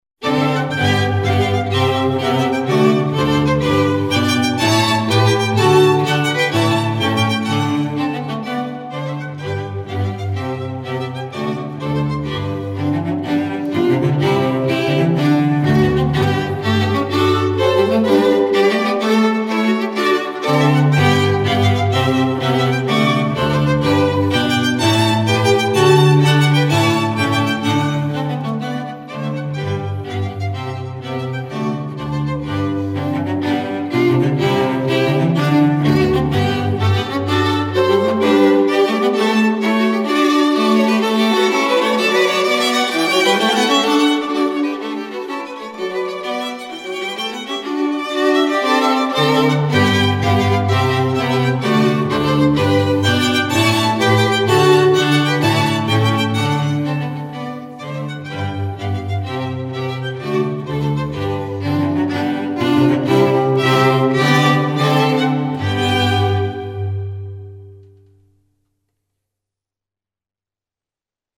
Música clásica